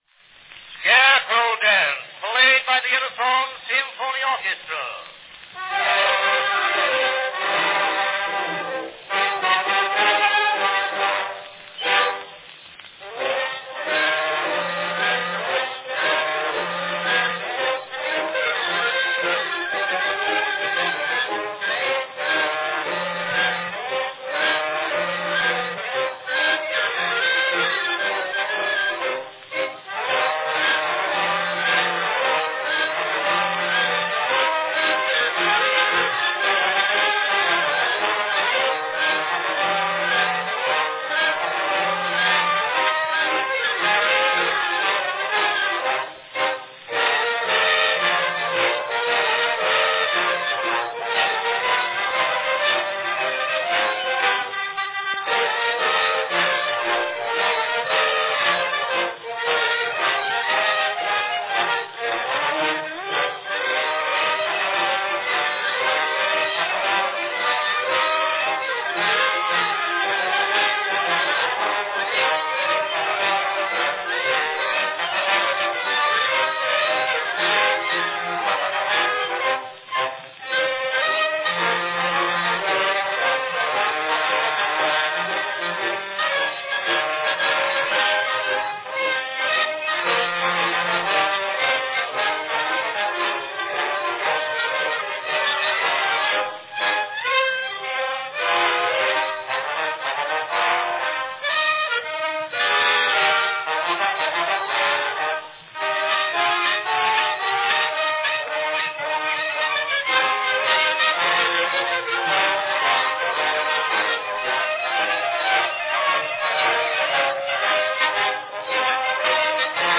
From 1903, a fine recording – on a 5-inch diameter Edison "concert" wax cylinder – of the Scarecrow Dance, performed by the Edison Symphony Orchestra.
Category Orchestra
Performed by Edison Symphony Orchestra
Announcement "Scarecrow Dance, played by the Edison Symphony Orchestra."
Enjoy this fun "characteristic" band piece.